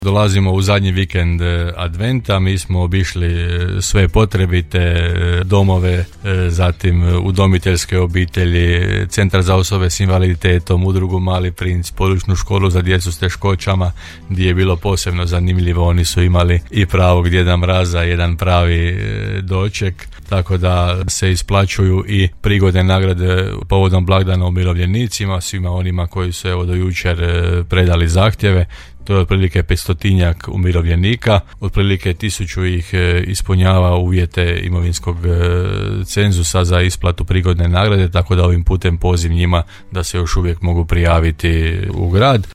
– poručio je gradonačelnik Janči u emisiji Gradske teme u programu Podravskog radija.